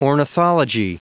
Prononciation audio / Fichier audio de ORNITHOLOGY en anglais
Prononciation du mot ornithology en anglais (fichier audio)